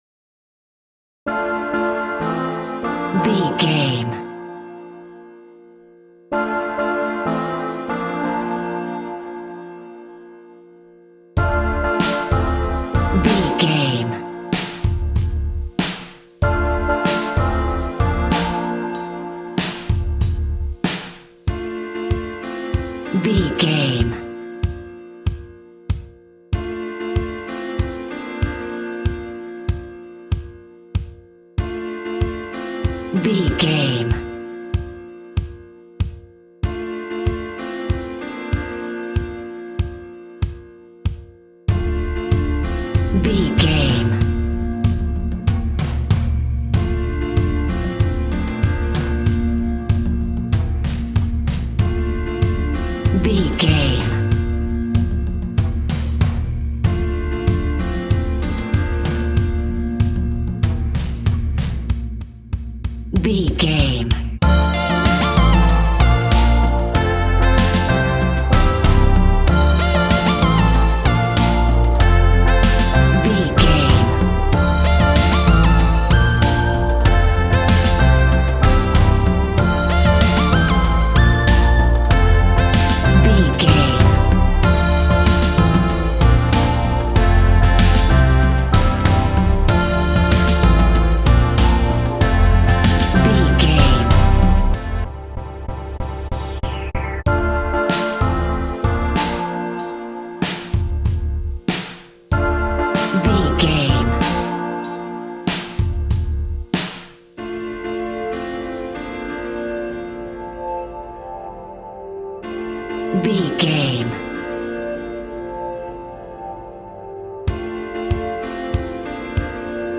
Aeolian/Minor
D
dark
futuristic
groovy
aggressive
repetitive
synthesiser
drum machine
electric piano
techno
trance
synth leads
synth bass